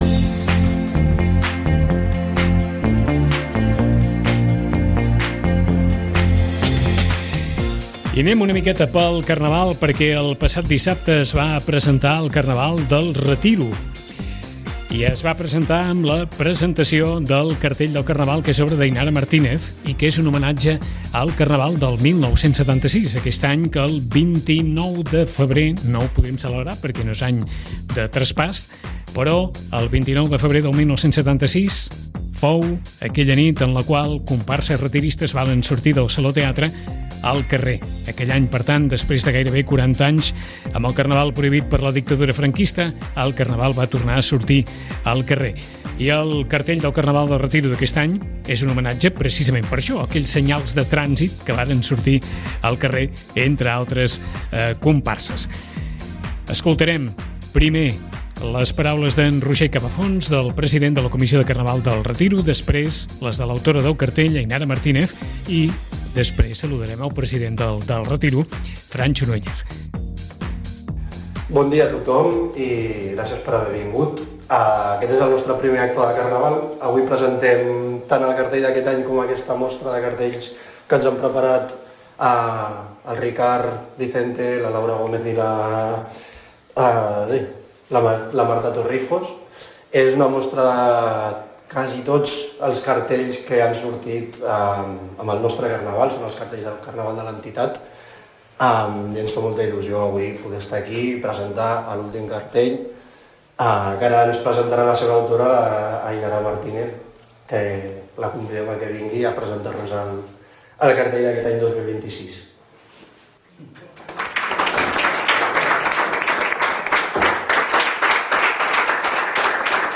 Us oferim l’àudio de la presentació del cartell i de l’exposició